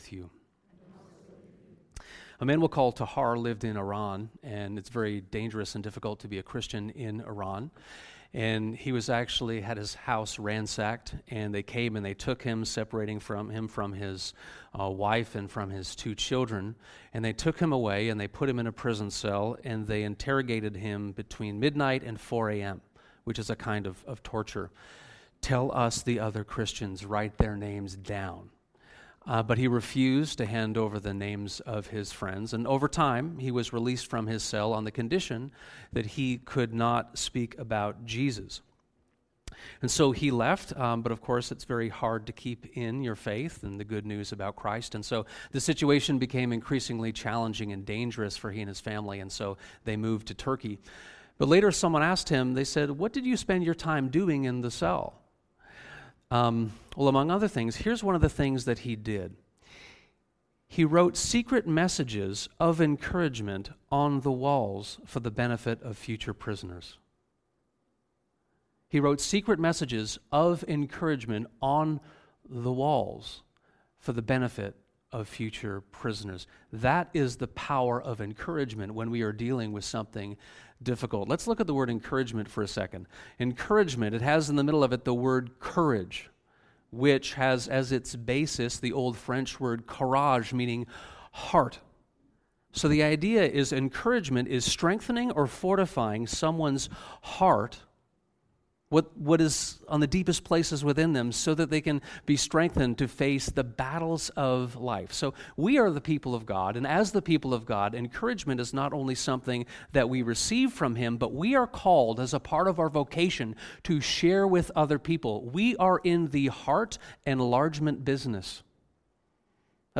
This sermon explores 2 John 1:1-13. With a pastoral heart, an elder statesman of the church writes to a local congregation to instruct and warn, but also to encourage.